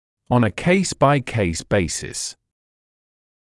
[ɔn ə keɪs baɪ keɪs ‘beɪsɪs][он э кейс бай кейс ‘бэйсис]в зависимости от конкретного случая